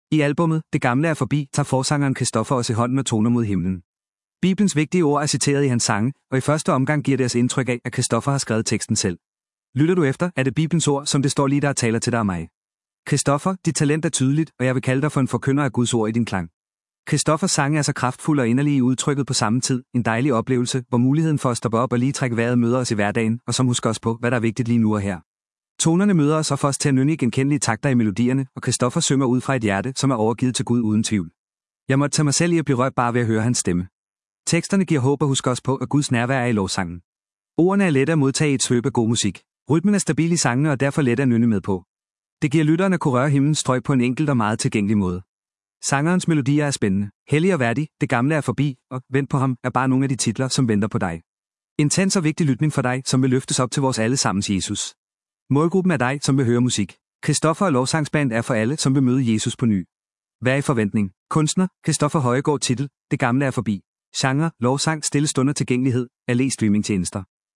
Genre: Lovsang / Stille Stunder